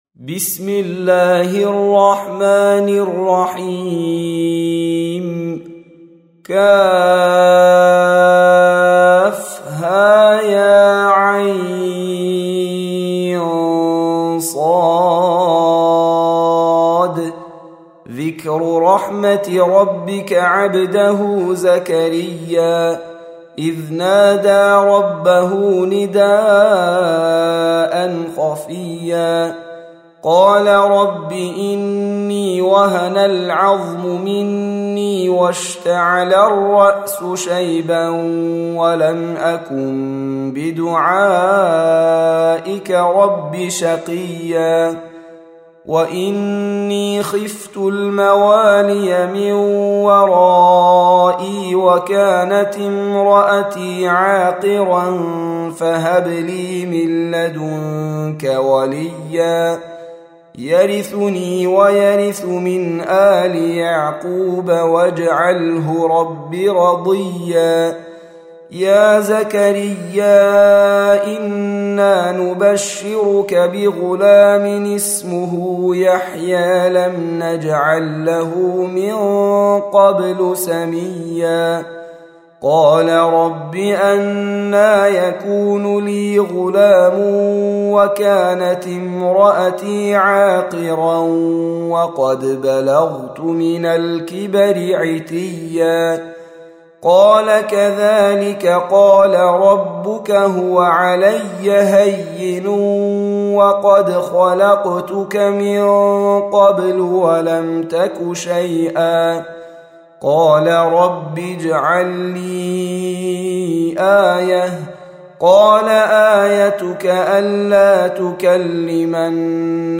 Surah Repeating تكرار السورة Download Surah حمّل السورة Reciting Murattalah Audio for 19.